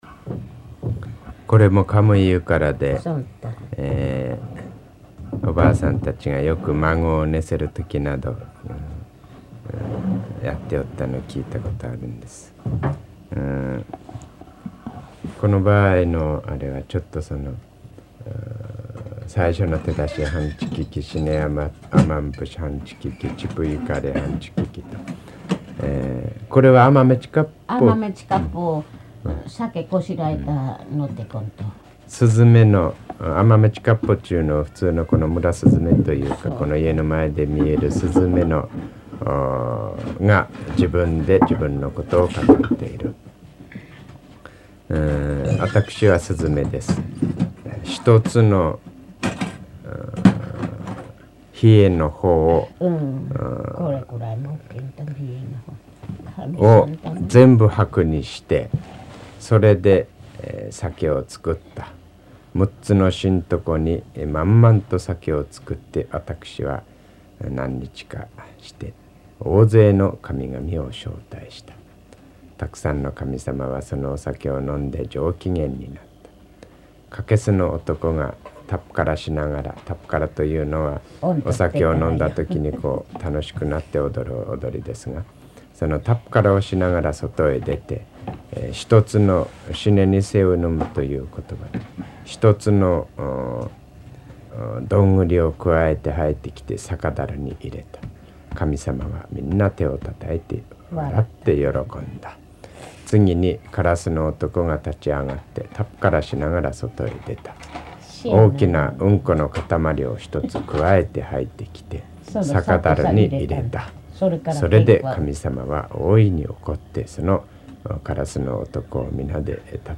[4-9 解説 commentary] 日本語音声 3:01